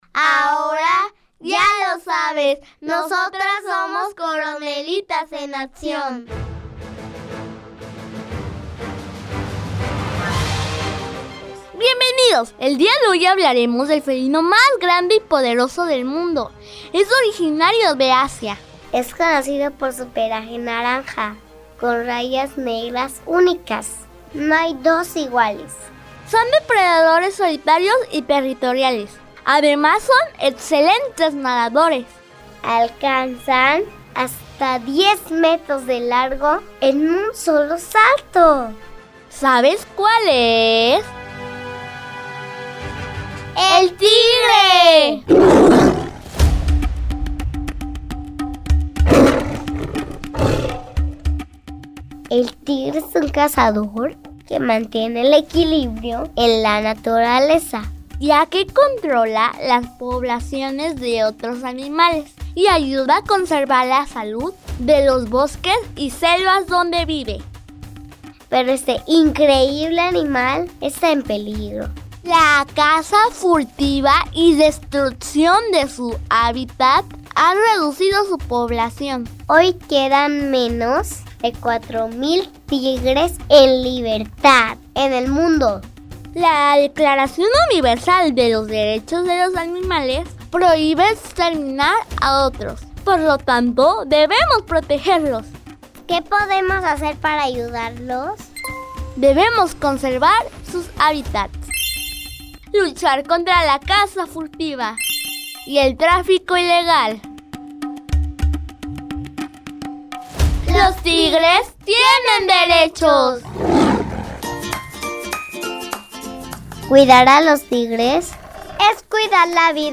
Son cápsulas radiofónicas que se encargan de hacer reflexionar acerca de los derechos de los animales, enfocándose en el conocimiento de especies en peligro de extinción o vulnerables, y haciendo referencia a la Declaración Universal de los Derechos de los Animales.